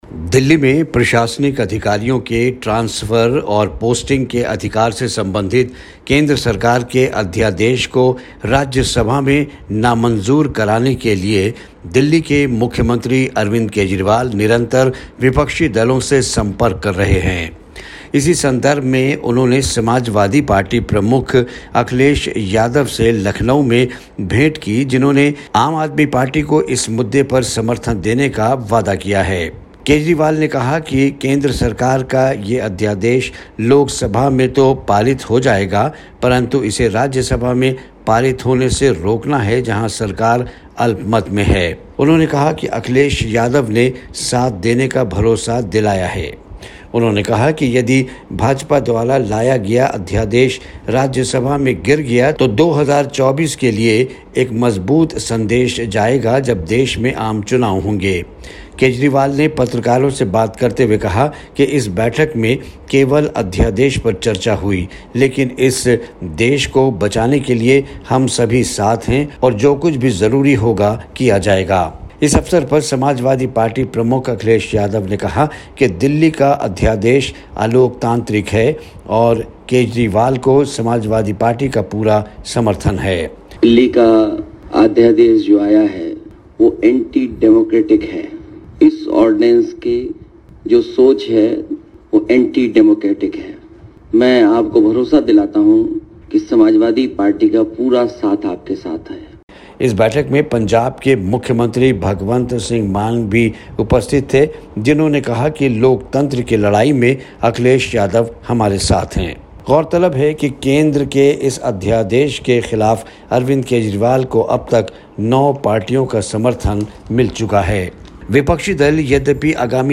बीजेपी के आर्डिनेंस के ख़िलाफ़ केजरीवाल का संघर्ष, रिपोर्ट